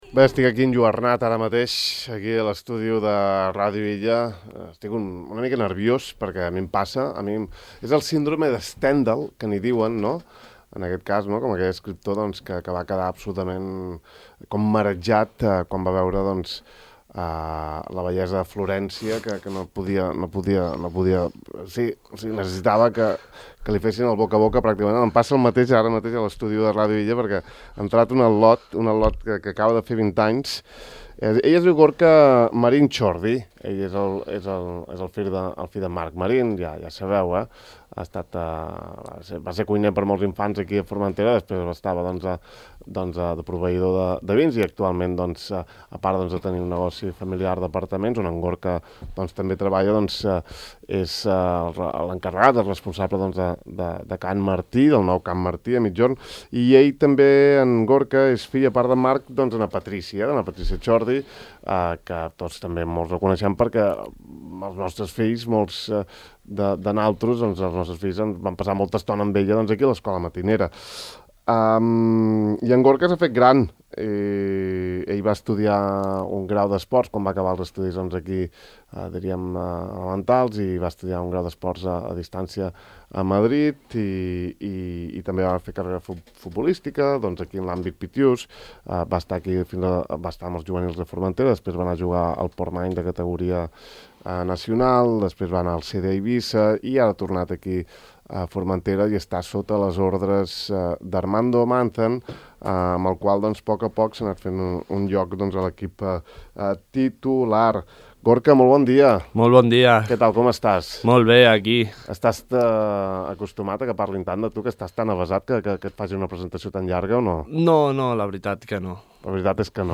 Avui, amb un to distès i bon humor, i hem conversat.